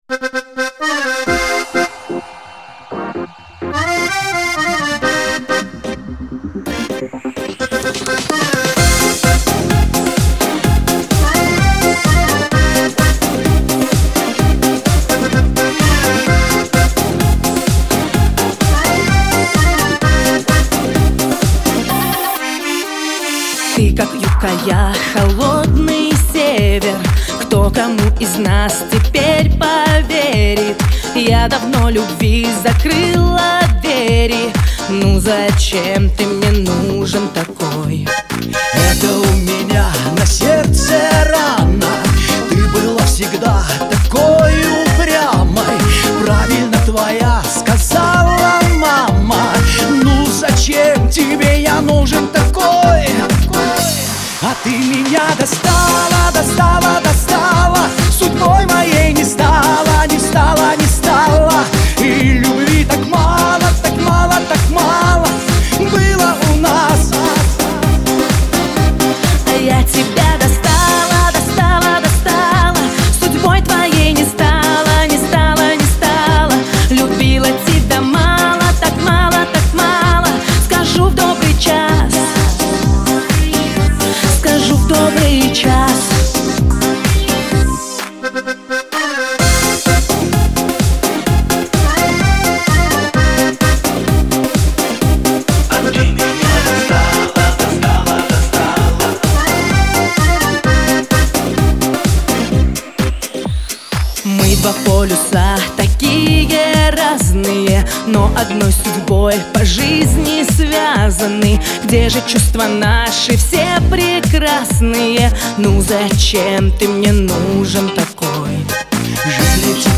исполнитель русского шансона родом из Иркутской области.
Всі мінусовки жанру Disco
Плюсовий запис